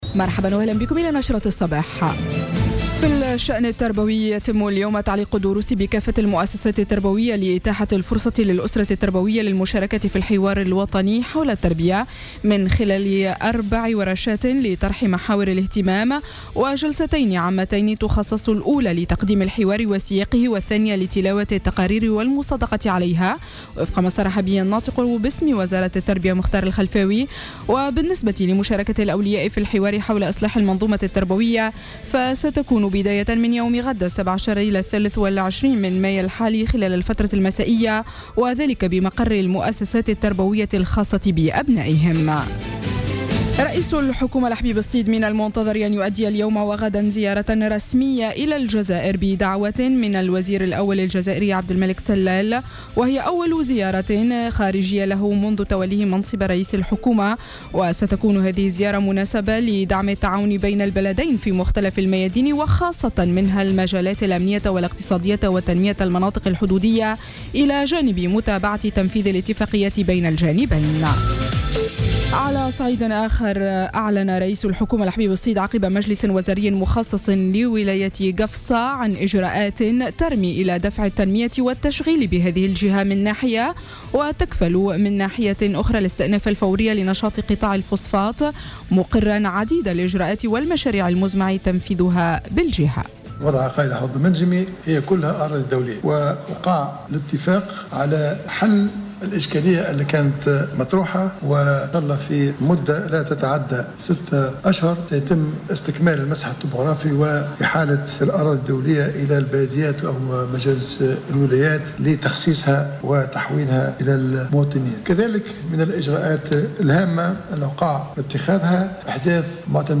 نشرة أخبار السابعة صباحا ليوم السبت 16 ماي 2015